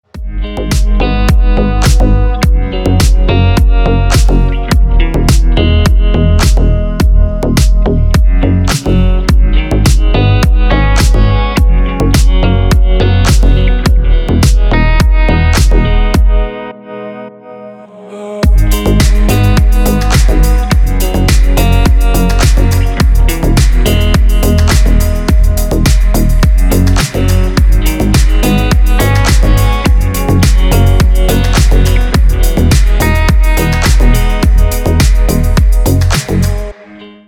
• Качество: Хорошее
• Категория: Красивые мелодии и рингтоны